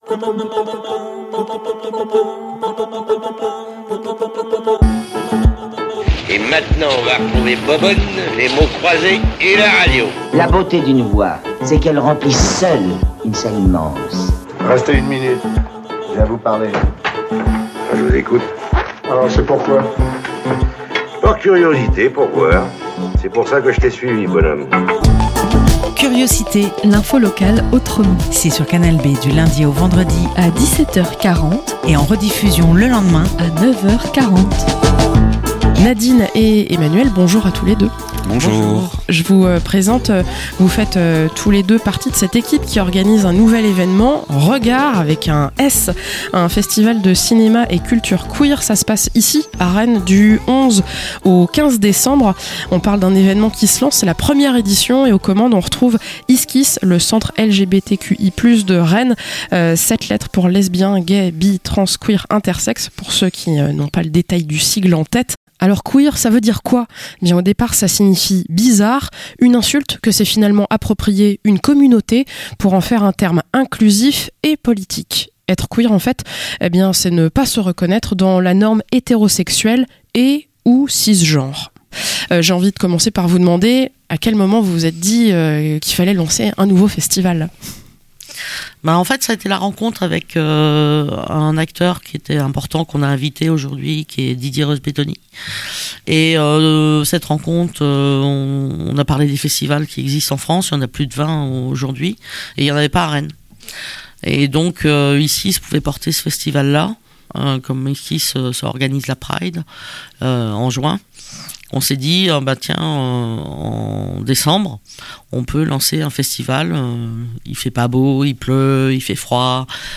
- Interview pour annoncer la première édition de Regard(s), festival cinéma et culture queer, à vivre du 11 au 15 décembre à Rennes.